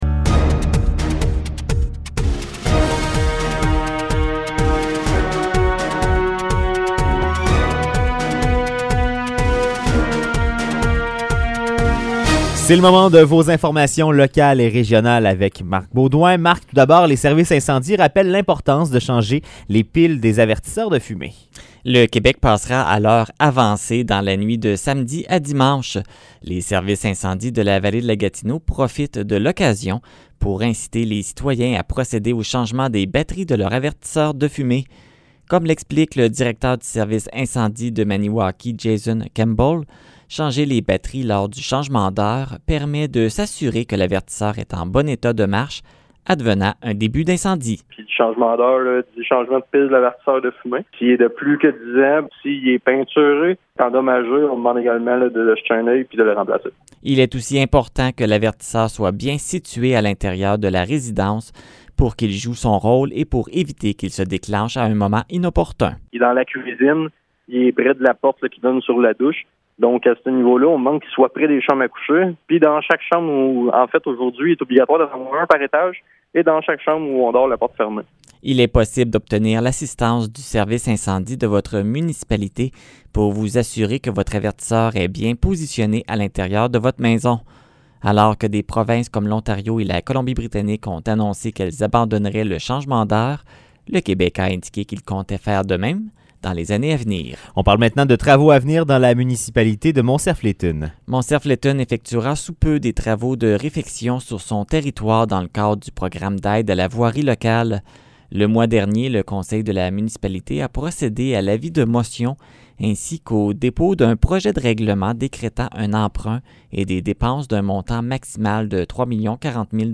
Nouvelles locales - 12 mars 2021 - 16 h